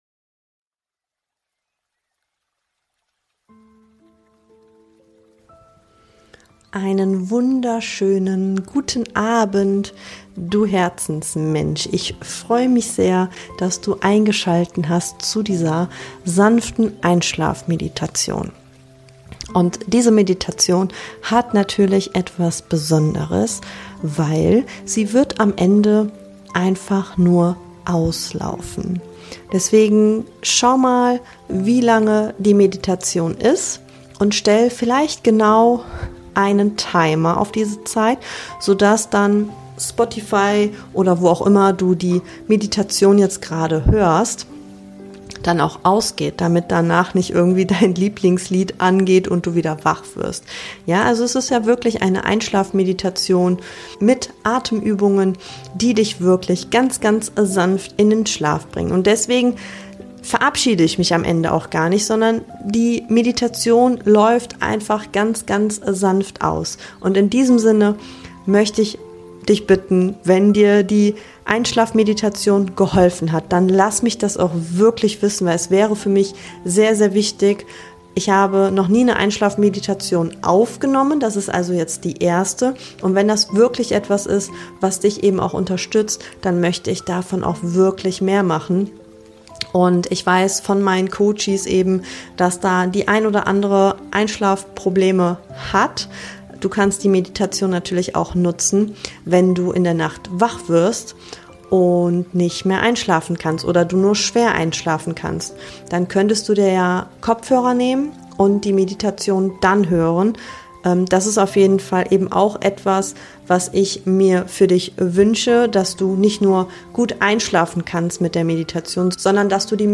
sanfte Einschlafmeditation für abends & nachts | mit Pianomusik ~ Boost your Mind to bright your Life Podcast
Erlebe eine geführte Meditation zum sanften Einschlafen.